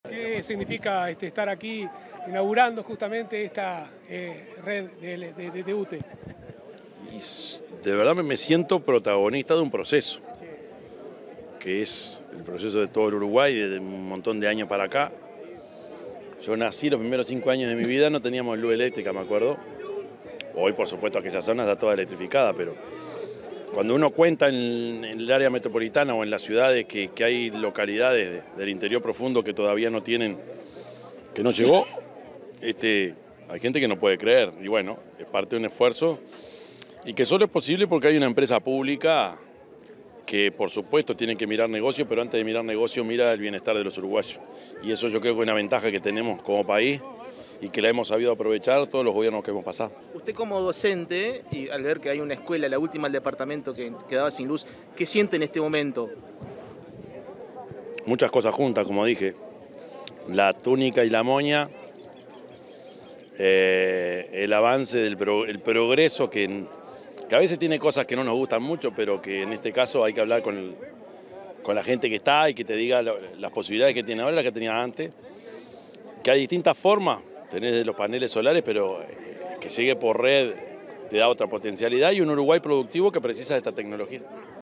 Declaraciones del presidente Yamandú Orsi 16/09/2025 Compartir Facebook X Copiar enlace WhatsApp LinkedIn Al finalizar el acto de inauguración de las obras de electrificación rural en la localidad de San Benito, Tacuarembó, el presidente de la República, Yamandú Orsi, efectuó declaraciones a la prensa.